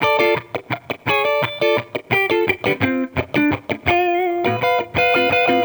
Index of /musicradar/sampled-funk-soul-samples/85bpm/Guitar
SSF_TeleGuitarProc1_85D.wav